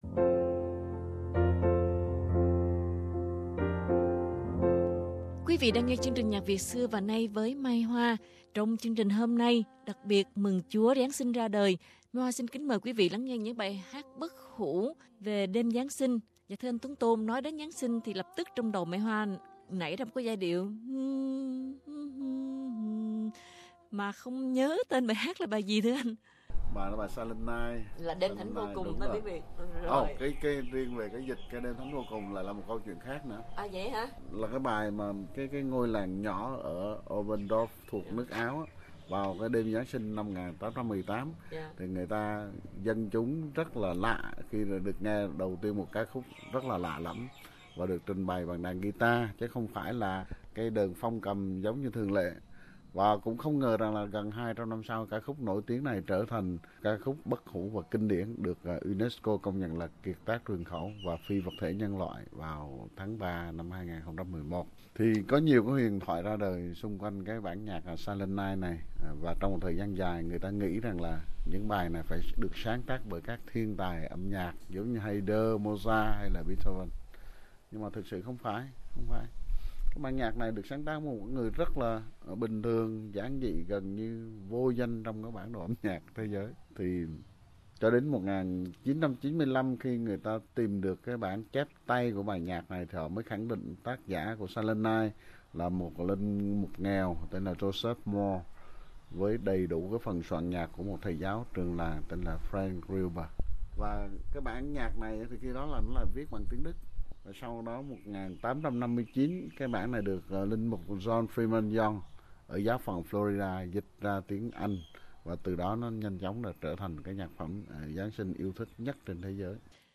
Chương trình Nhạc Việt Xưa & Nay đặc biệt mừng Giáng Sinh với những bài hát bất hủ do các ca sĩ quốc tế và Việt Nam trình bày.